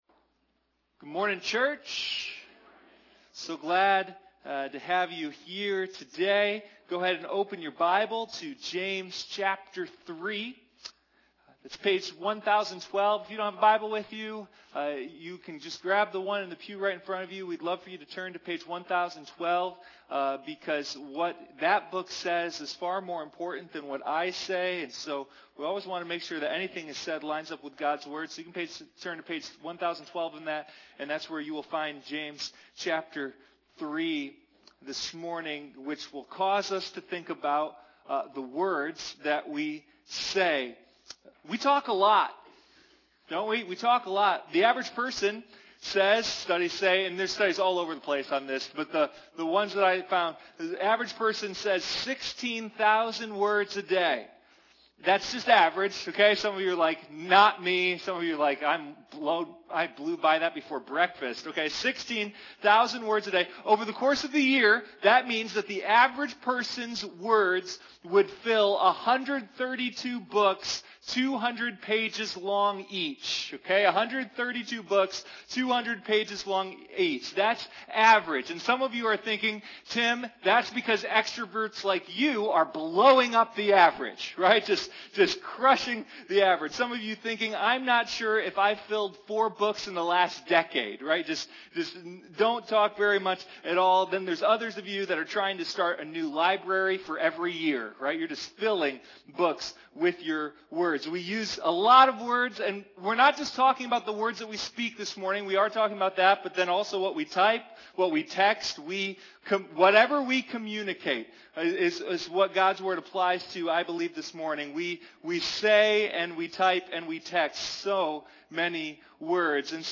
Sunday Morning Living Faith: The Book of James